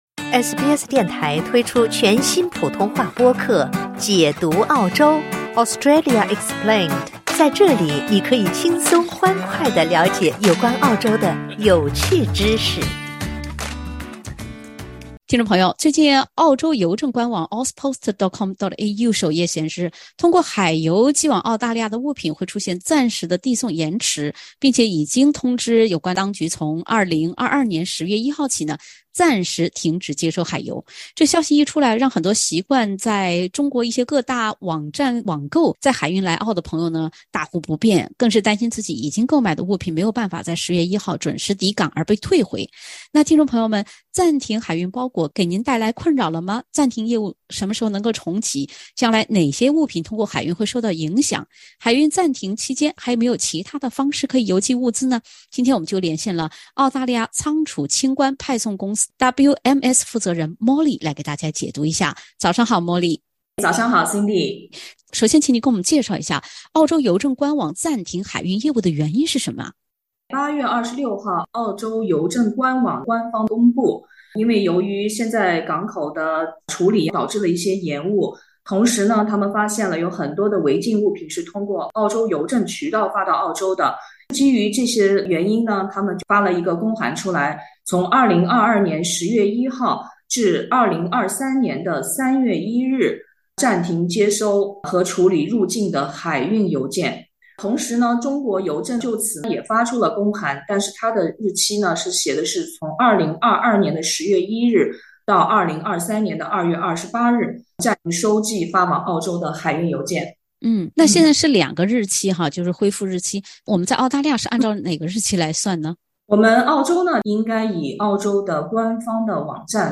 澳洲本土仓储清关派送公司负责人解答从中国寄件来澳是否还能走水运及特别注意事项。